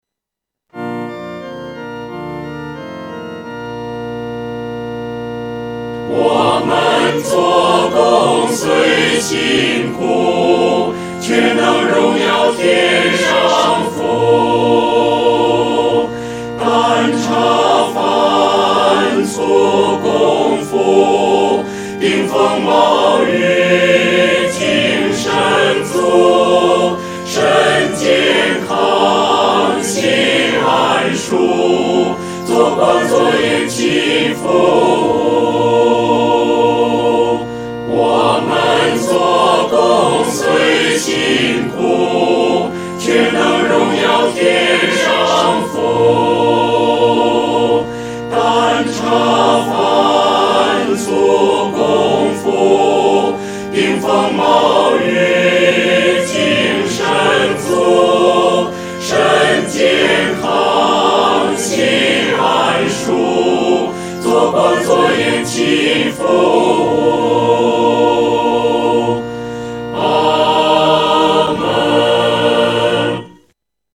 合唱
四声
曲调很朴素，用的是五声音阶，农村信徒很容易上口。